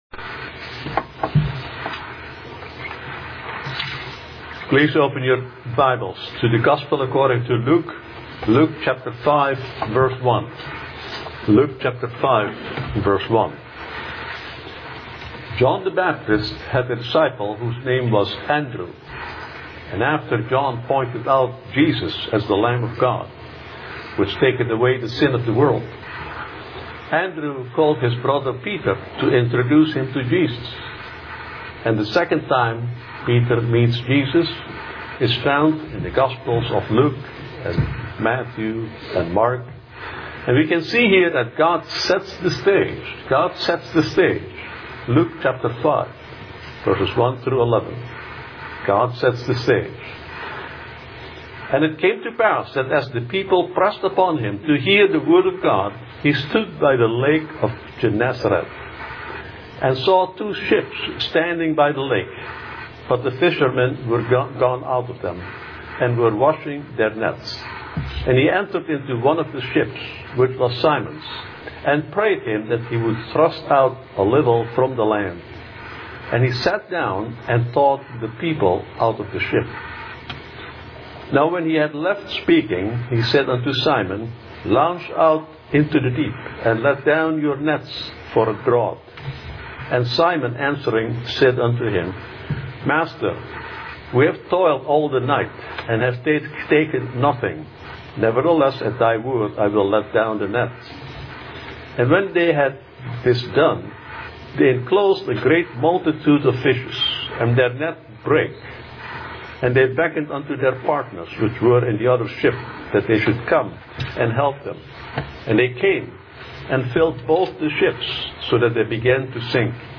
This is an audio sermon